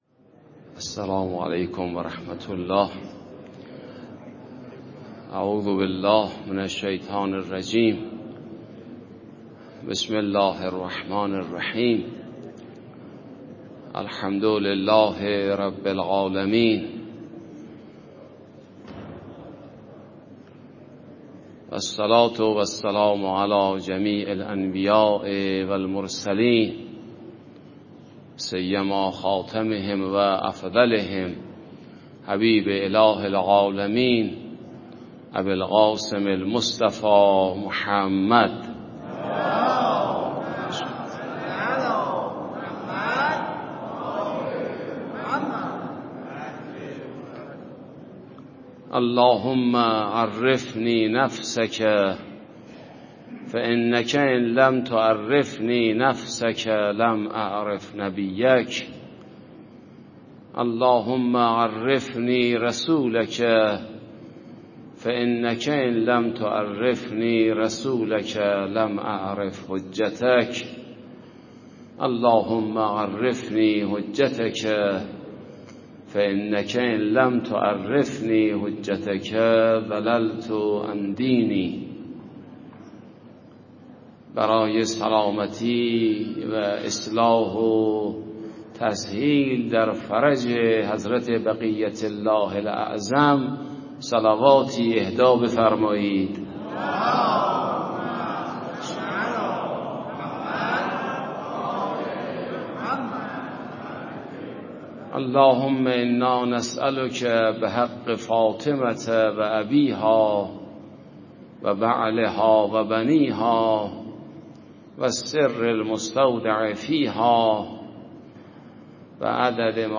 سخنرانی به مناسبت ایام فاطمیه در مسجد امام حسن عسکری تهران شب اول + صوت